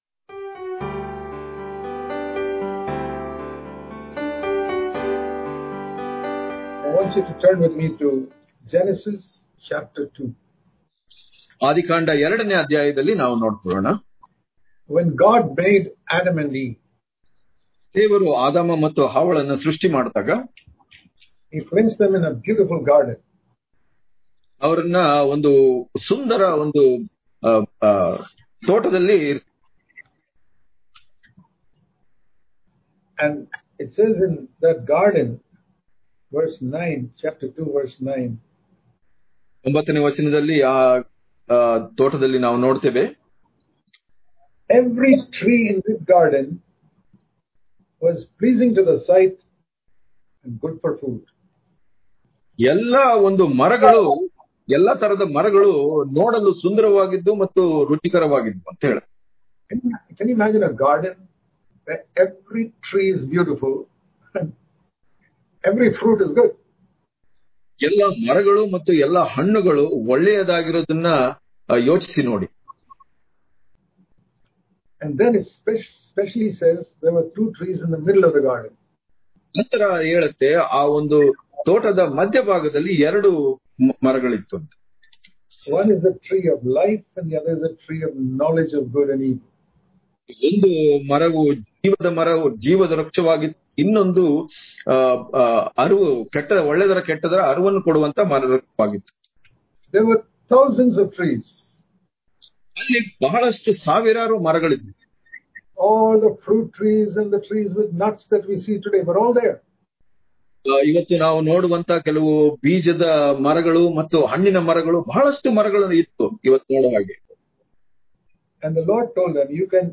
May 16 | Kannada Daily Devotion | Are You Gaining Life Or Knowledge From The Bible? Daily Devotions